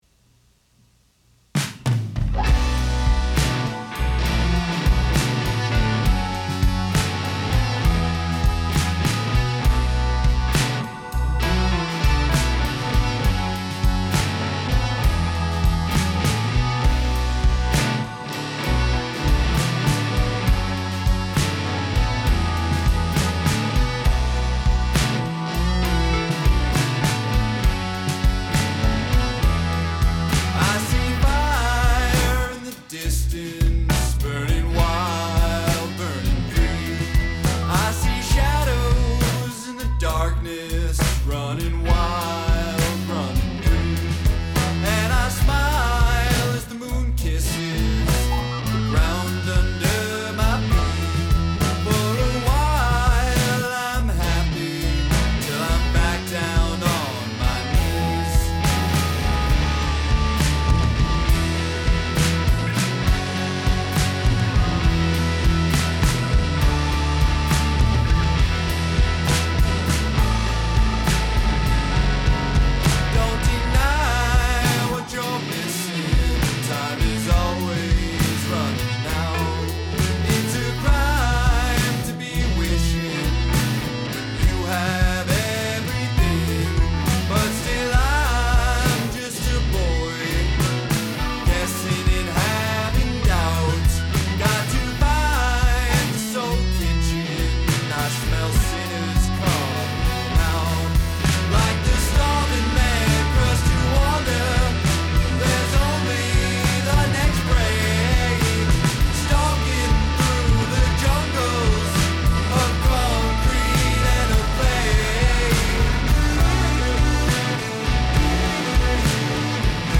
heavy blues rock track, full of swagger and edge